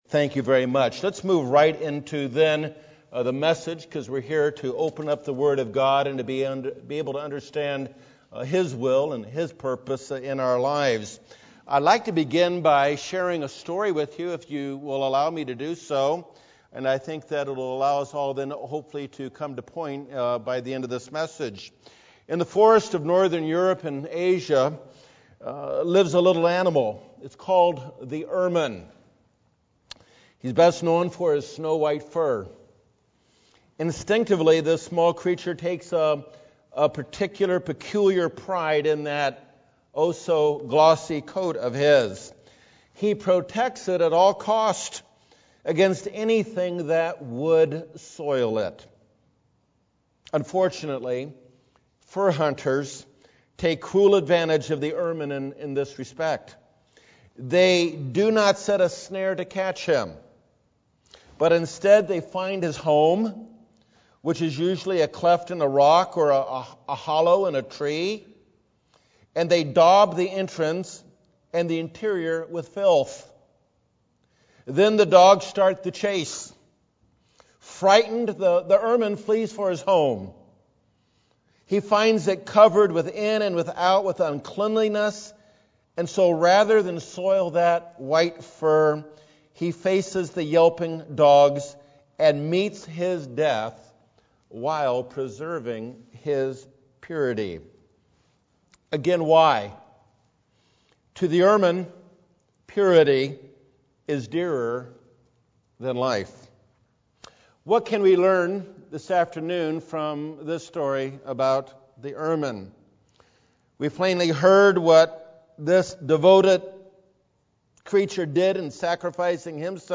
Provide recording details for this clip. We are called to be holy before God. This message, given during the Days of Unleavened Bread, admonishes us to pursue righteousness in our thoughts and actions.